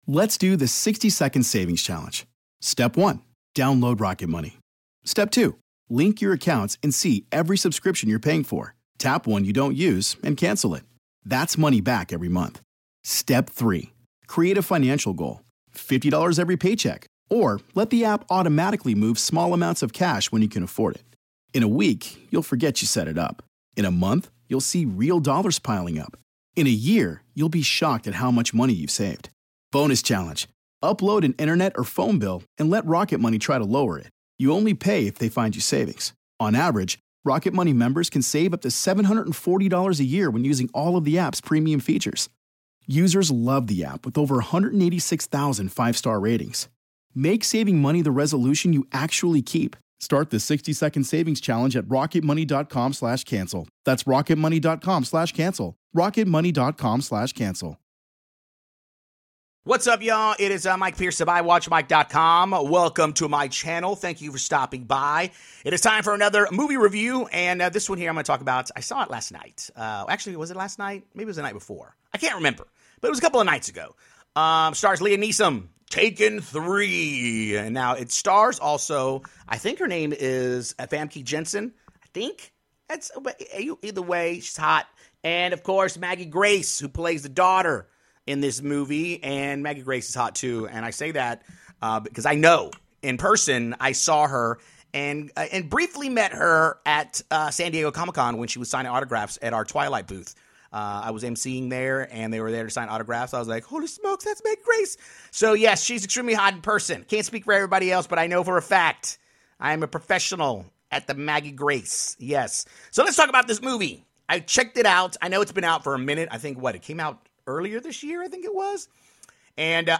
Review: Taken 3